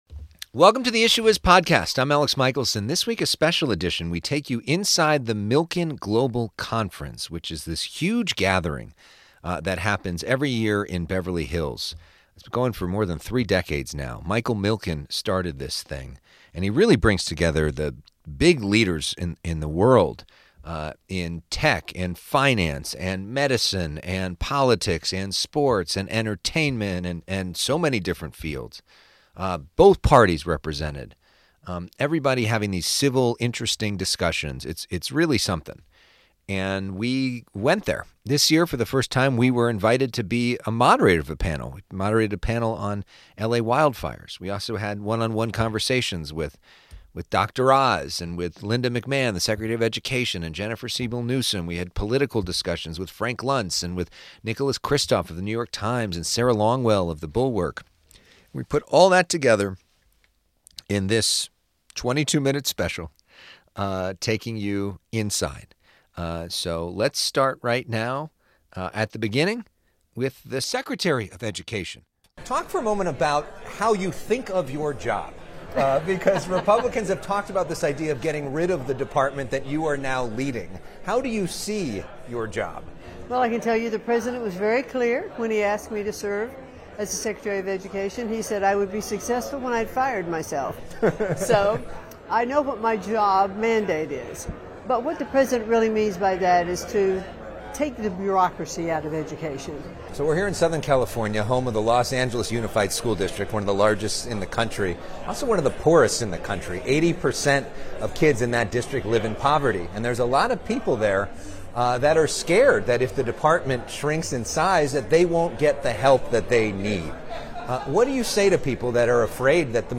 Inside the Milken Global Conference with a panel on LA wildfire response and one-on-one with Dr. Mehmet Oz, California First Partner Jennifer Siebel Newsom and U.S. Education Secretary Linda McMahon.